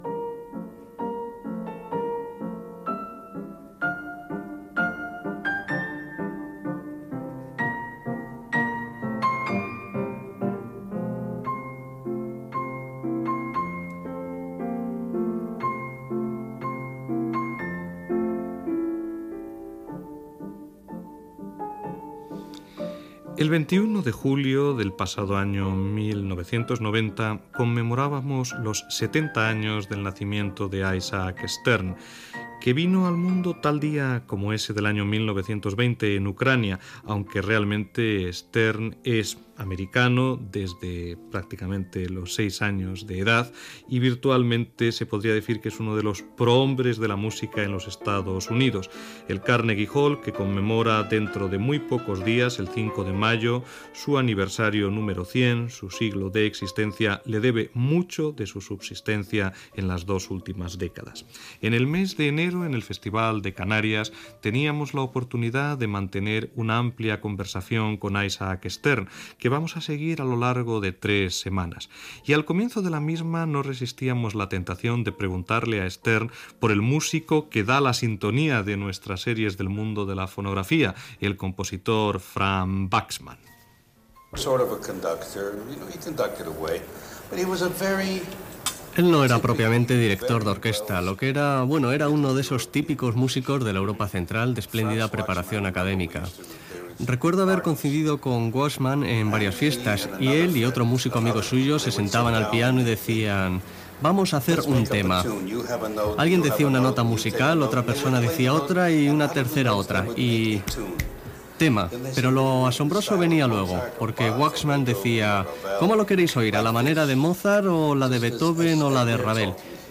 Entrevista feta al violinista Isaac Stern, feta quan va visitar les illes Canàries el gener de l'any 1990